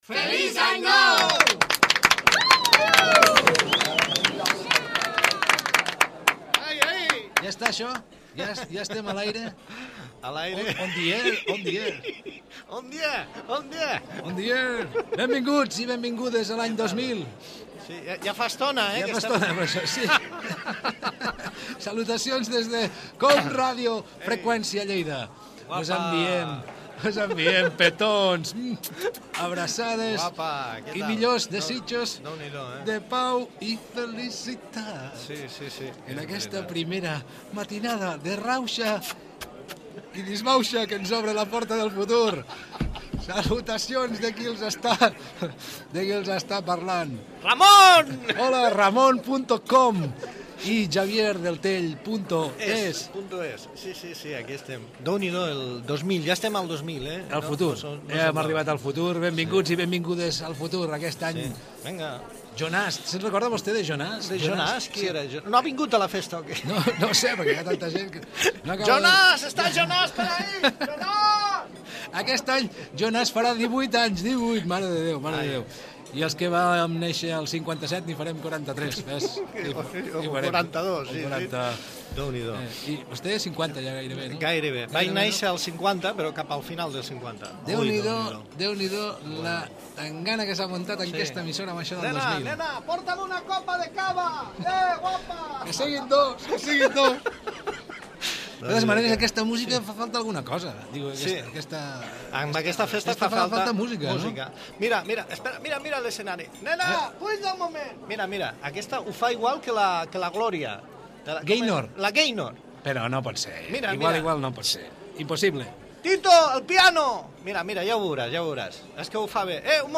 Inici del programa de cap d'any. Comentaris i tema musical
Entreteniment
FM